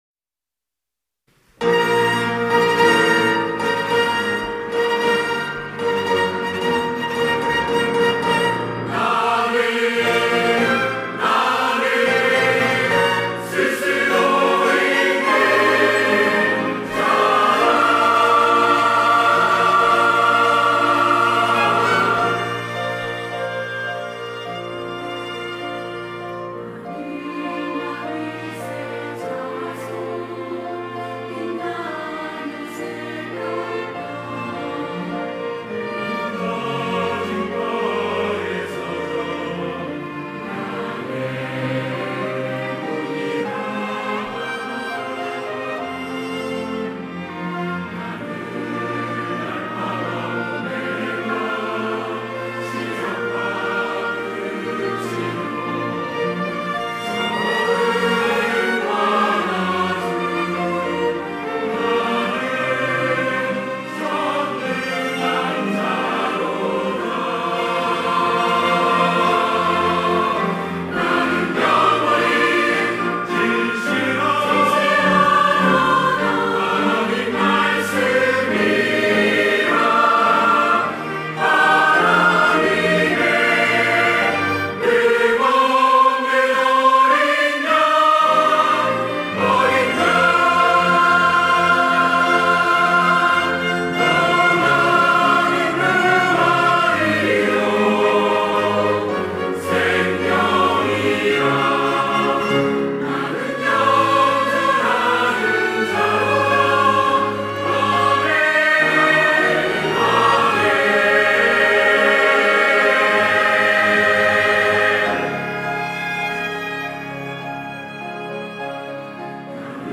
호산나(주일3부) - I AM
찬양대